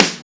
SNARE42.wav